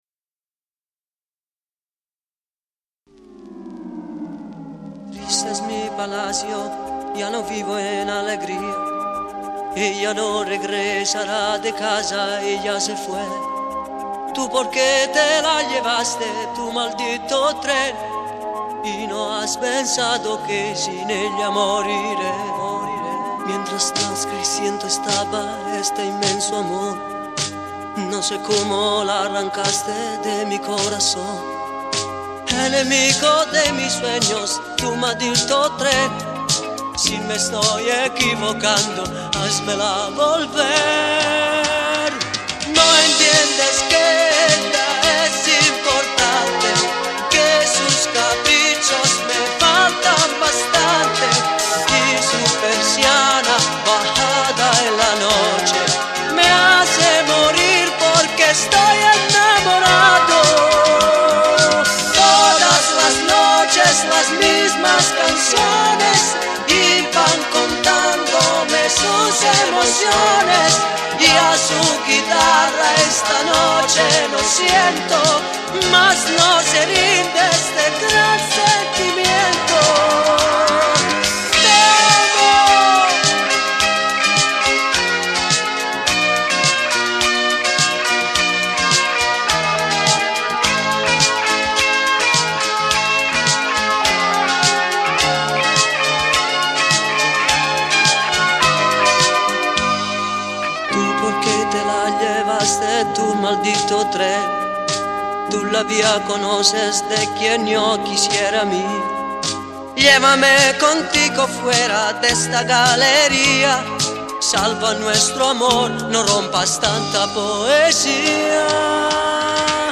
Coro
Batteria
Chitarra
Basso
Pianoforte, Sintetizzatori e Tastiere